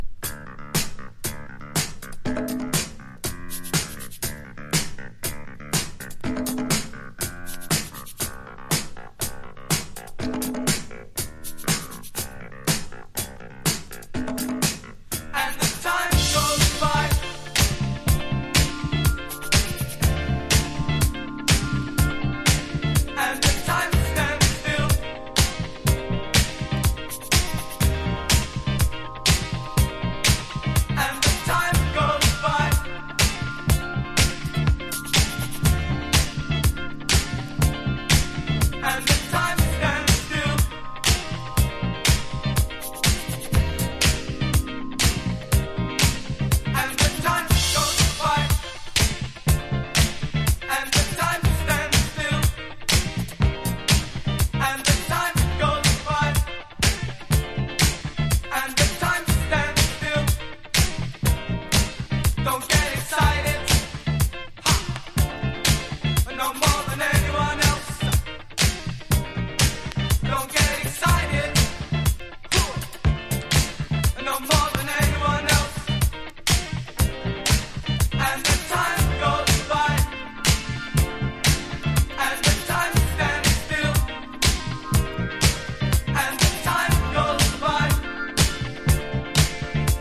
FUNK / DEEP FUNK
GARAGE# NEWWAVE DISCO
所によりノイズありますが、リスニング用としては問題く、中古盤として標準的なコンディション。